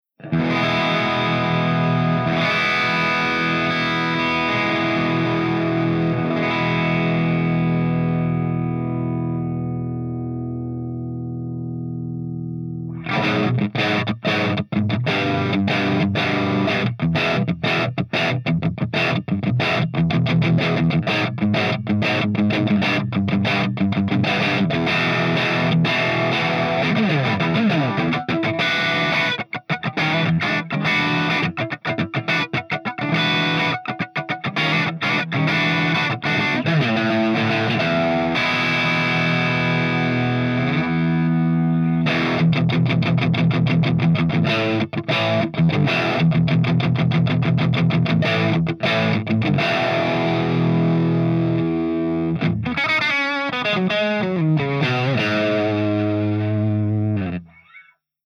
123_MARSHALLJCM800_CH2DRIVE_GB_SC